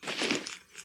equip_diamond5.ogg